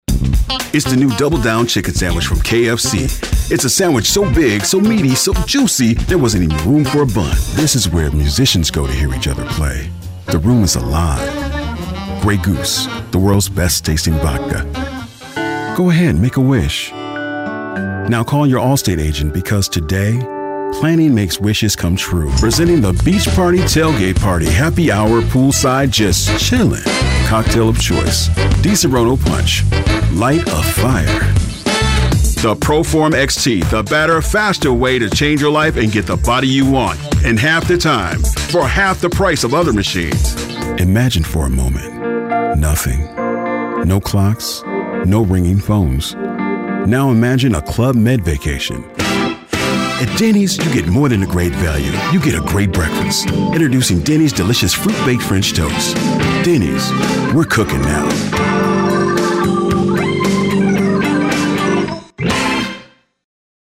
middle west
My vocal arsenal consists of narrative/movie trailers and PA announcer voice-overs. My voice ID can be described as “smooth, urban, middle-aged, authoritative, confident, assured, strong, masculine and assertive”.